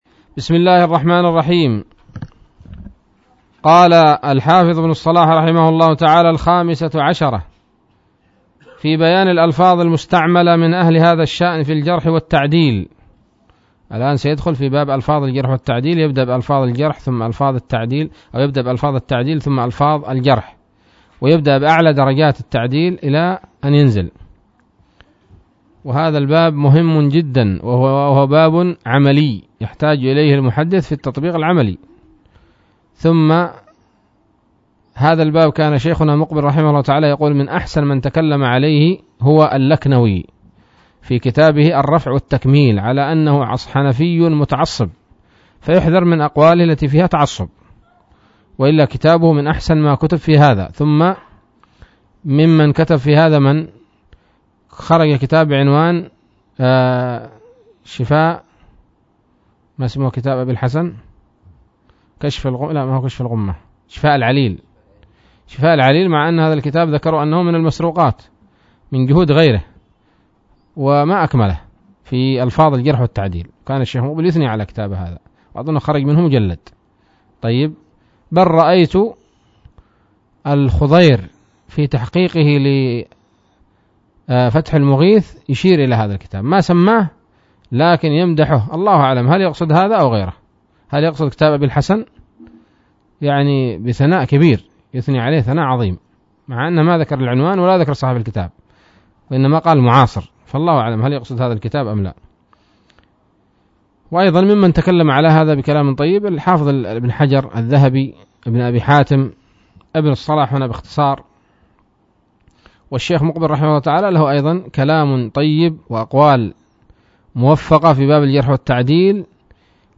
الدرس الخامس والخمسون من مقدمة ابن الصلاح رحمه الله تعالى